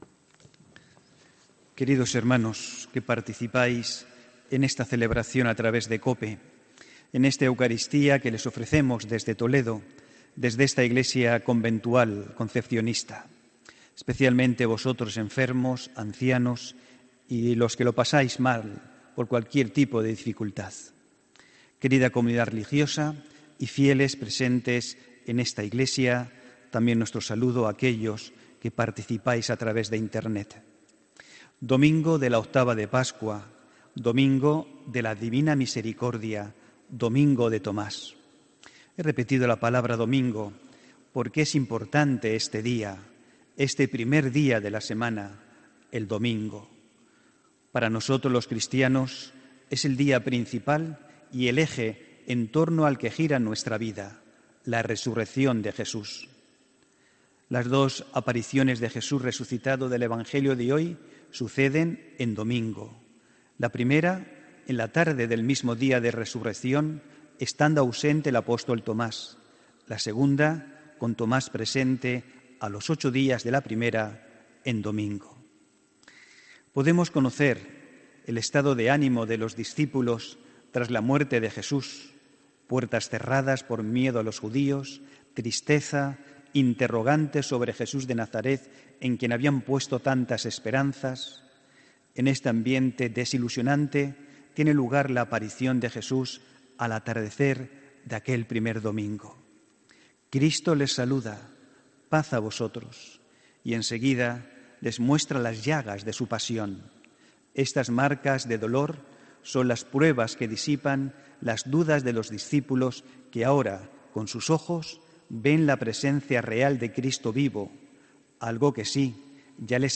HOMILÍA 28 ABRIL 2019